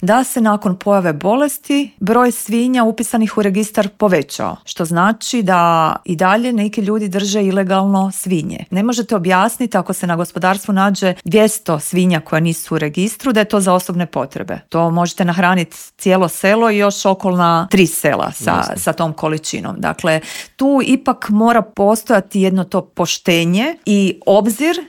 U popodnevnim satima sastaje se i saborski Odbor za poljoprivredu čija je predsjednica Marijana Petir gostovala u Intervjuu Media servisa: